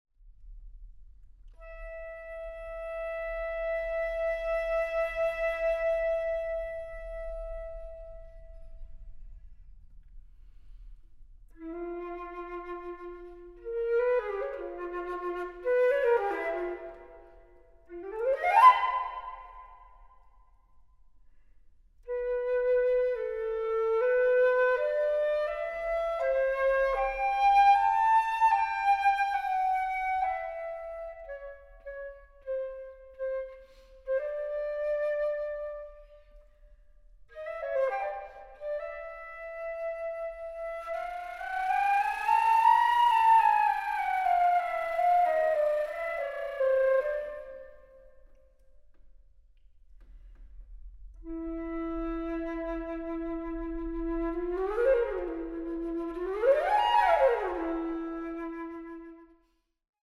Flöte
Harfe